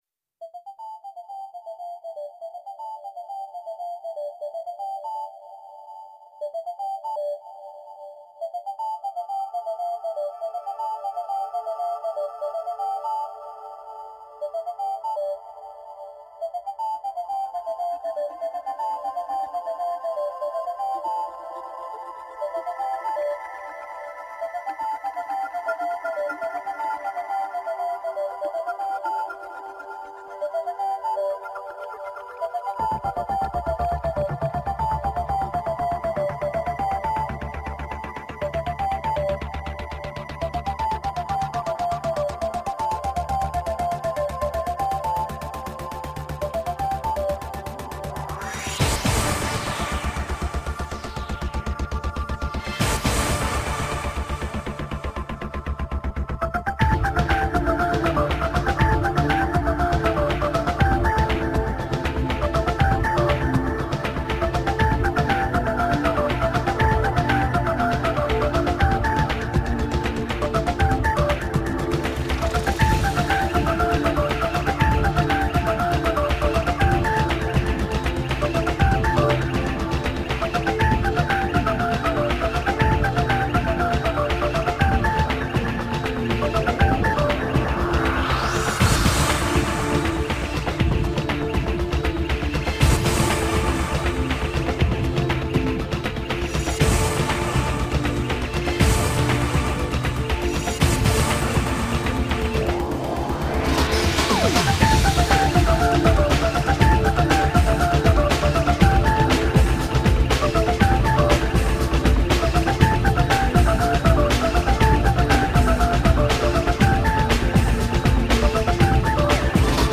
超级电音
涉及分类：电子音乐 Electronica
涉及风格：电子音乐 Electronica、舞蹈音乐 Dance